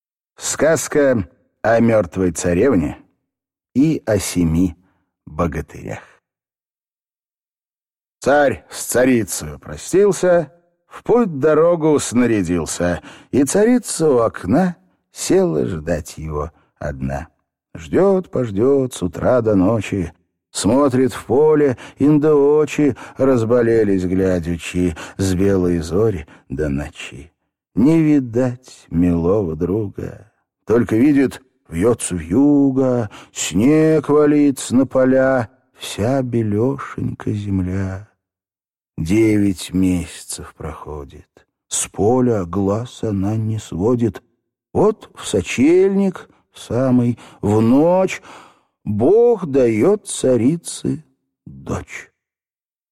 Аудиокнига Сказка о мёртвой царевне и о семи богатырях | Библиотека аудиокниг